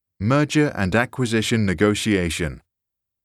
[mur-jer] [and] [ak-wuh-zish-uh n] [ni-goh-shee-ey-shuh n]